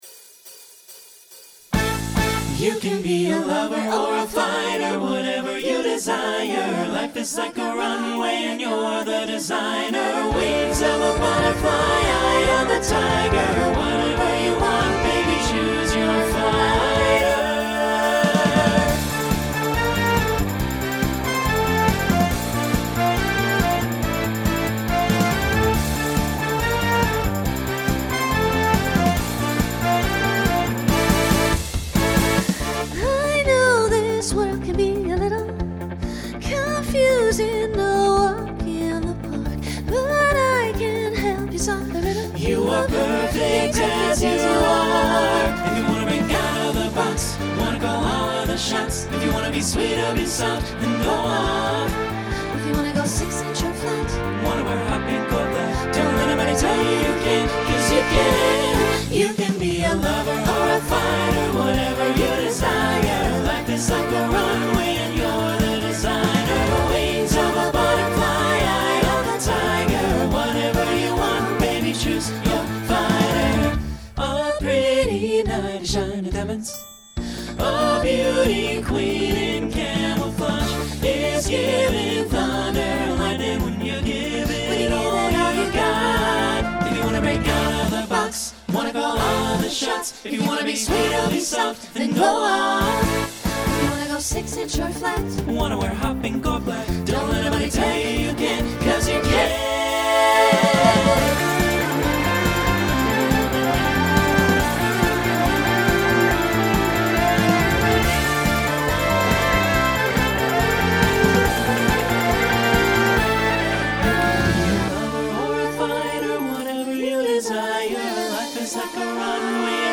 Genre Broadway/Film , Pop/Dance
Instrumental combo
Voicing SATB